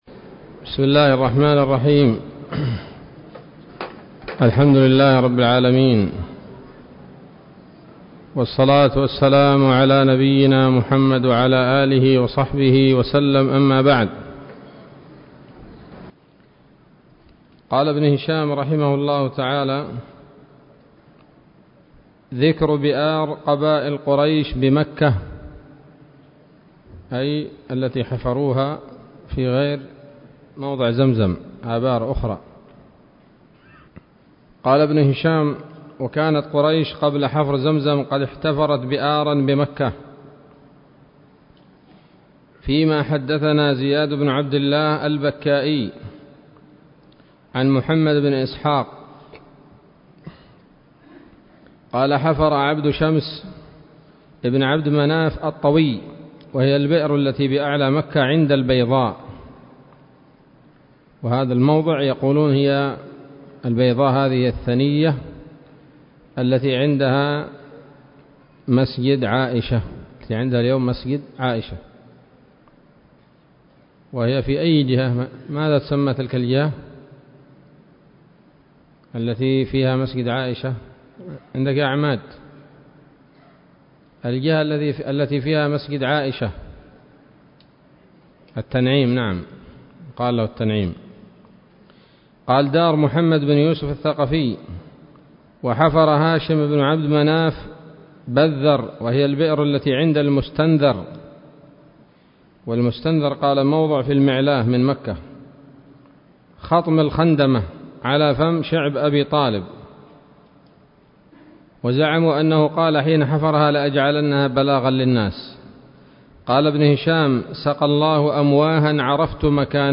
الدرس الثاني عشر من التعليق على كتاب السيرة النبوية لابن هشام